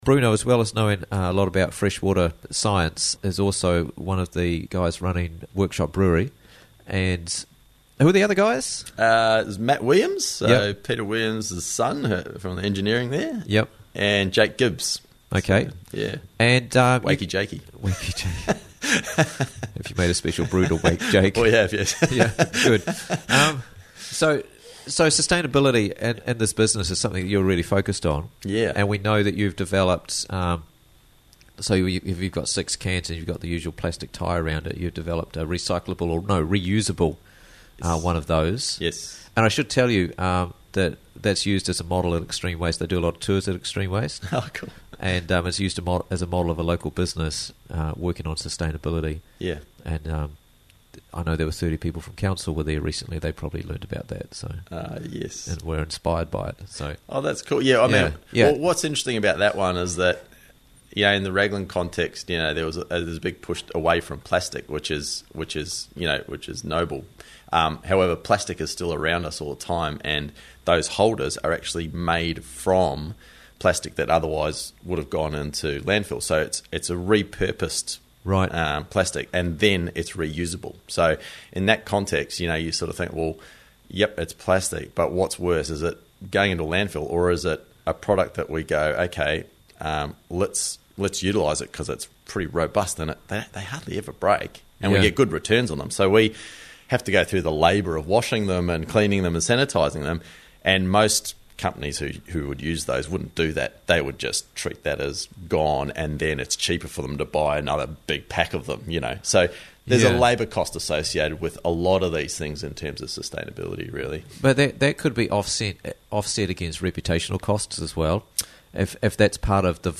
Workshop Brewery's Sustainability Journey - Interviews from the Raglan Morning Show